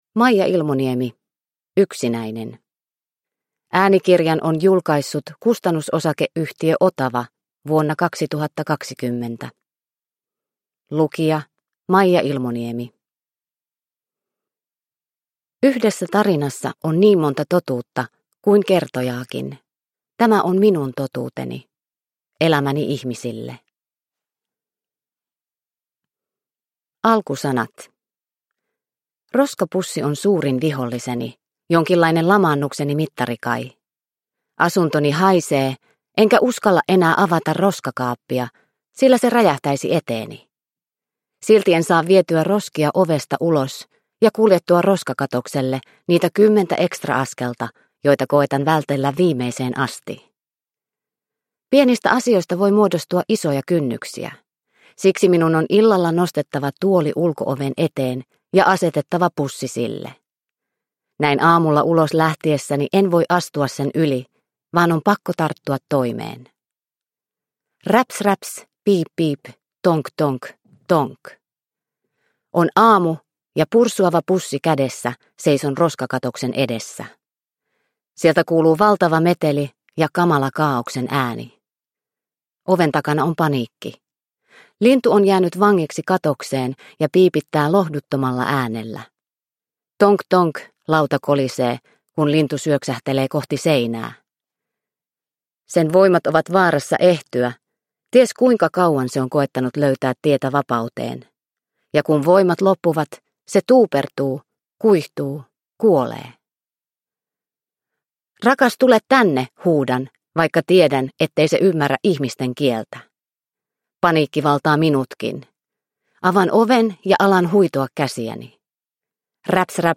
Yksinäinen – Ljudbok